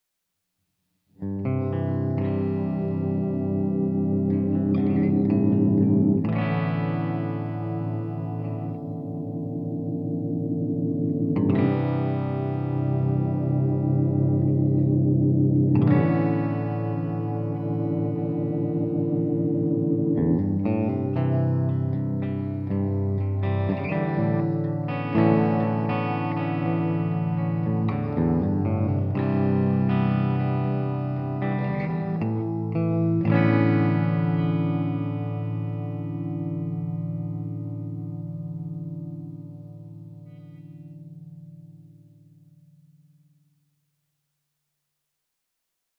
Micro-Reverb.wav